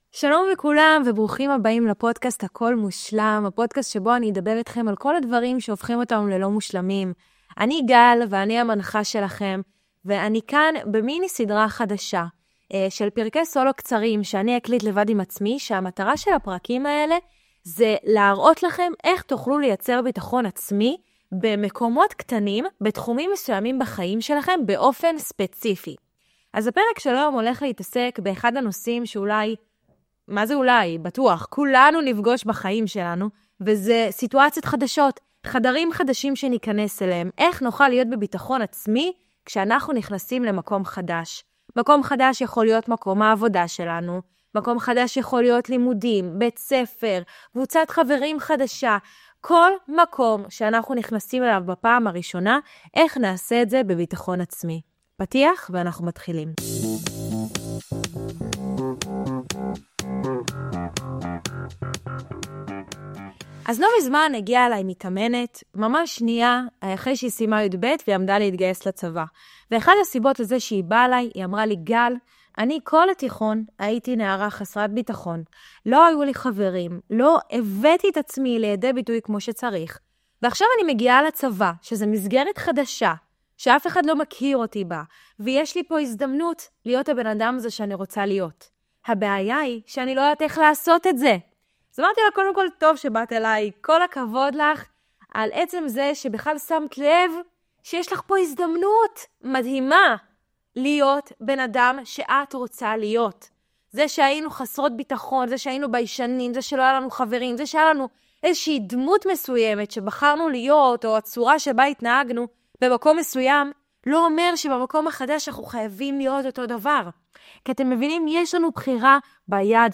מיני עונה של פרקי סולו קצרים וממוקדים שיעזרו לכם לפתח ביטחון עצמי במקומות ספציפיים בחייכם!